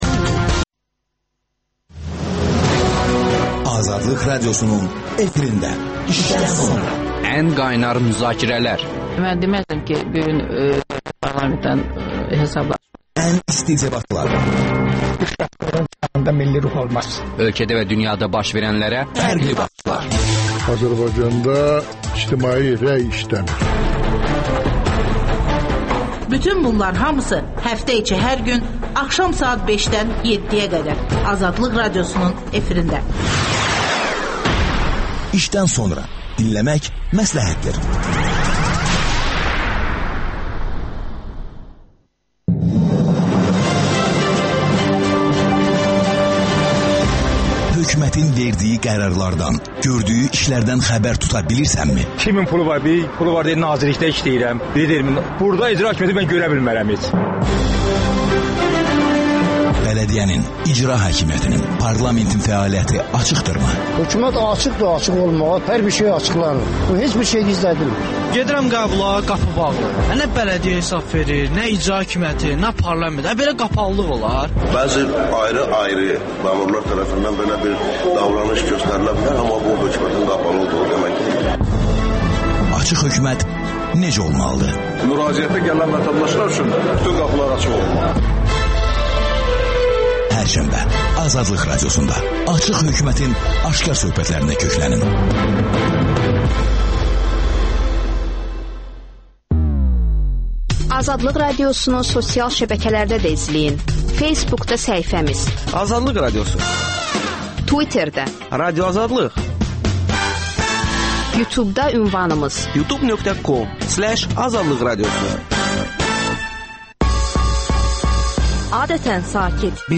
söhbət